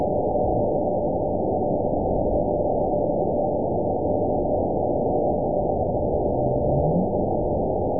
event 917381 date 03/30/23 time 09:23:51 GMT (2 years, 1 month ago) score 9.40 location TSS-AB01 detected by nrw target species NRW annotations +NRW Spectrogram: Frequency (kHz) vs. Time (s) audio not available .wav